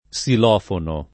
vai all'elenco alfabetico delle voci ingrandisci il carattere 100% rimpicciolisci il carattere stampa invia tramite posta elettronica codividi su Facebook silofono [ S il 0 fono ] o xilofono [ k S il 0 fono ] s. m. (mus.)